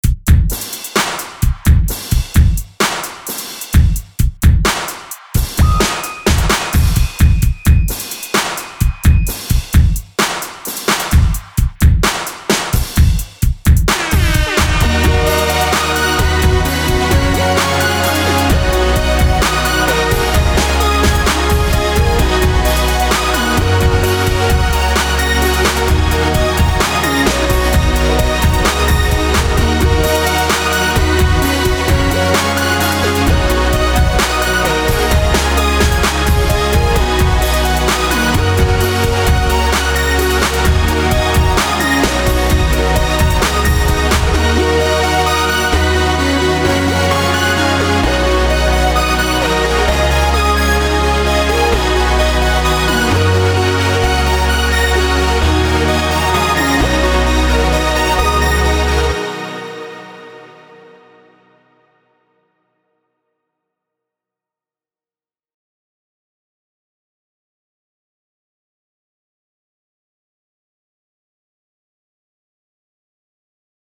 2 Thumb Up 미디어 듣기 게시판 정복하러 옴. 아직 미완성! 믹싱도 안했고!